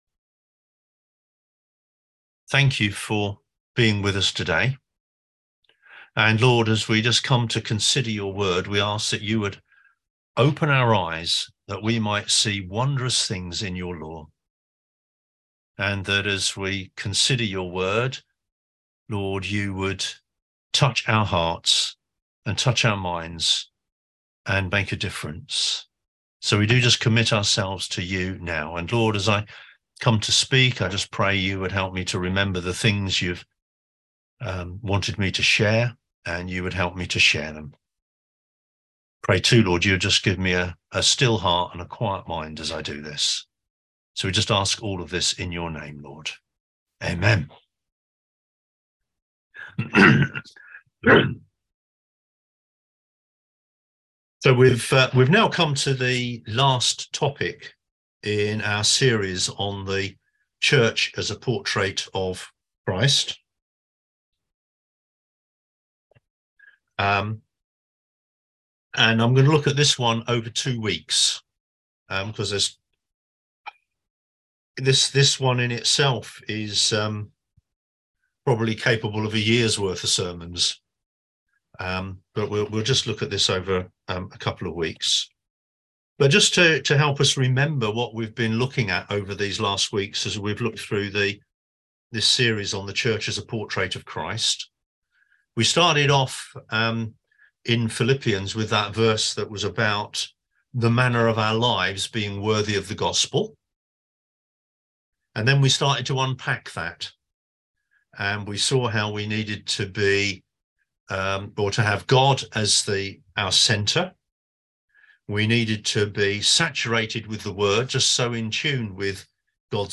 Passage: Romans 8:5-8, Romans 12:2, Galatians 5:16-26, Ephesians 5:18 Service Type: On-Line Sunday Service (English)